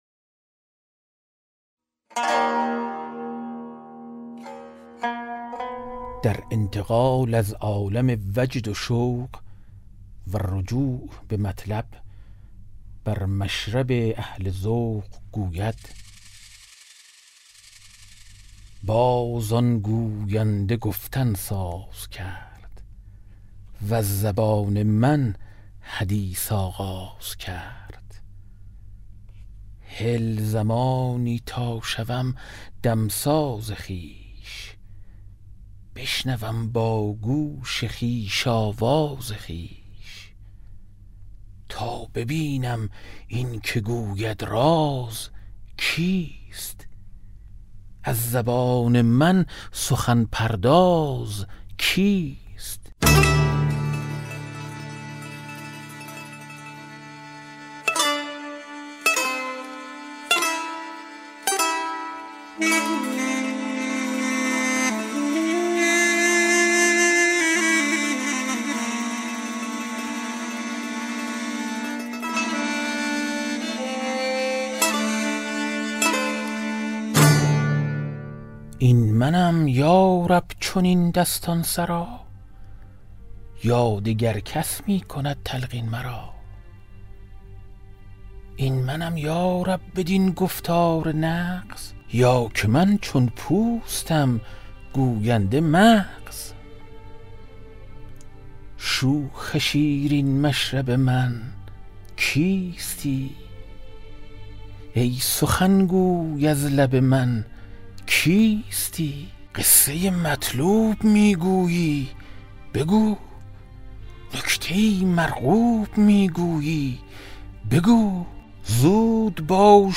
کتاب صوتی گنجینه الاسرار، مثنوی عرفانی و حماسی در روایت حادثه عاشورا است که برای اولین‌بار و به‌صورت کامل در بیش از ۴۰ قطعه در فایلی صوتی در اختیار دوستداران ادبیات عاشورایی قرار گرفته است.